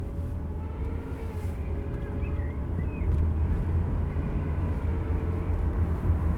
X308 Gearbox Noise
I have the following noise on my 2000 year XJ8.
1. The noise is only from 1-2 and 2-3rd gears.
Jaguar+XJ8+Gearbox+Noise.wav